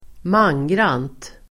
Ladda ner uttalet
mangrant adverb, in full numbers , to a man Uttal: [²m'an:gran:t] Definition: med samtliga personer närvarande, med alla utan undantag Exempel: ställa upp mangrant (volunteer in full force) original bottling , mangrant